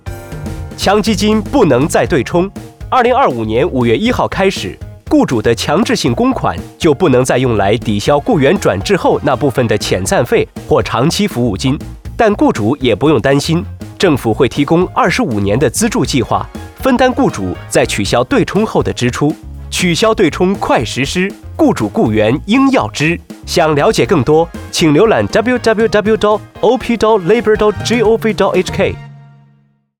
取消強積金「對沖」安排電台廣播粵語 (8.33 MB) /普通話 (8.28 MB)